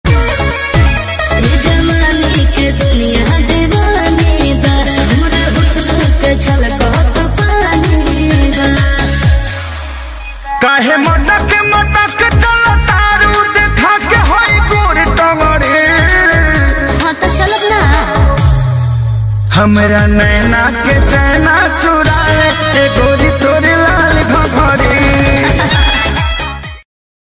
Sensual, catchy vibes for calls, alerts.